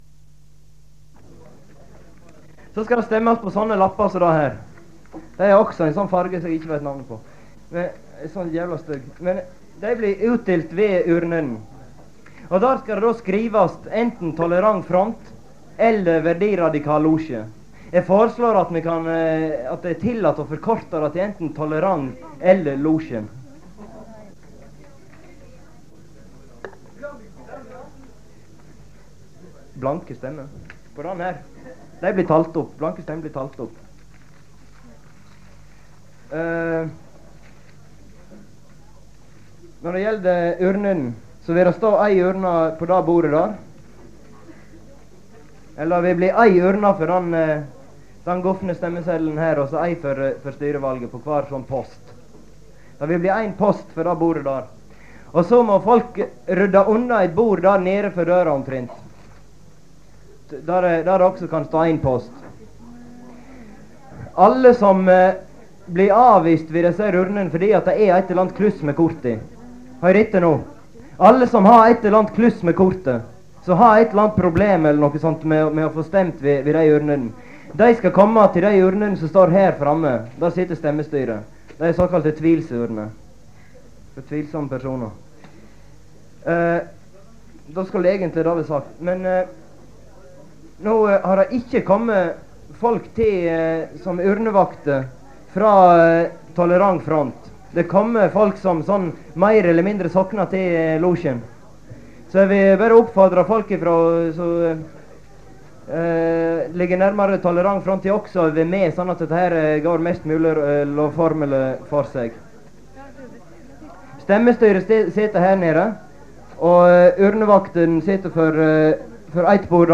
Det Norske Studentersamfund, Generalforsamling, 26.11.1982 (fil 1-2:4)